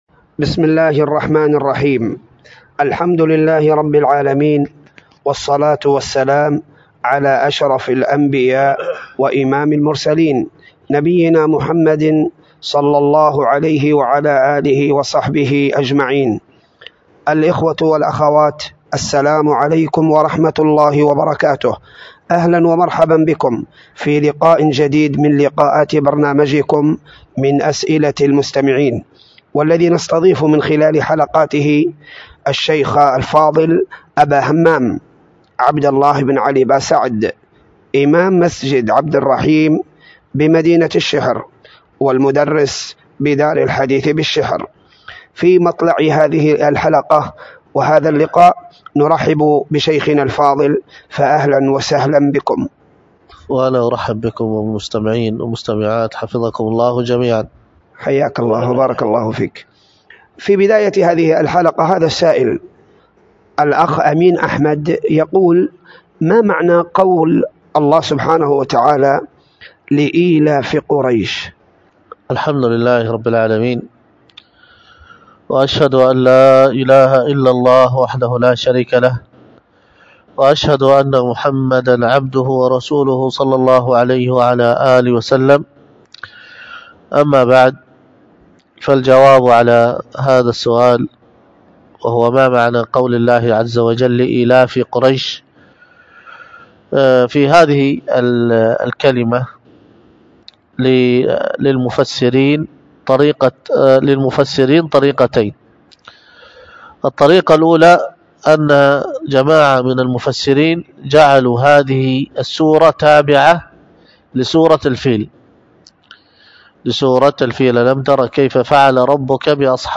الخطبة بعنوان الإحسان في العشر الأواخر من رمضان، وكانت بمسجد التقوى بدار الحديث بالشحر 19 رمضان 1440 ﻫ ألقاها